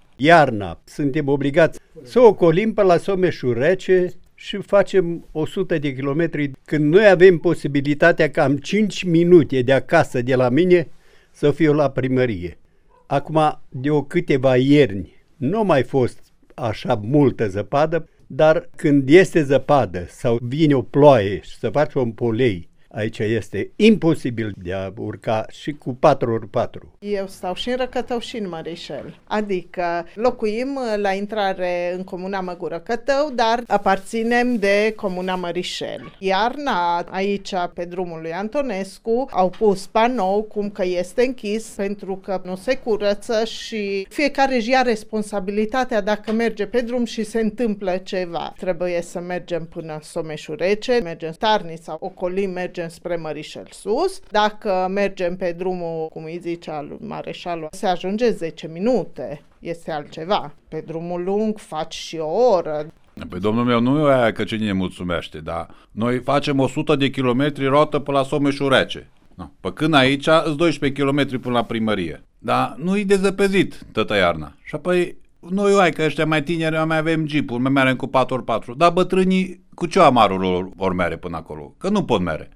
Cei aflați în această situație se plâng că, de câte ori drumul se închide, au de făcut peste 100 de kilometri pentru orice problemă pe care o au de rezolvat la primărie:
oameni-din-Racatau.wav